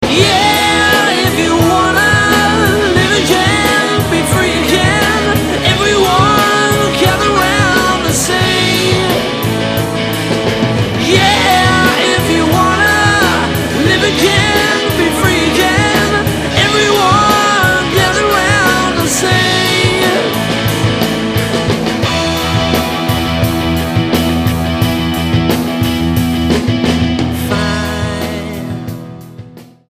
STYLE: Rock
great guitar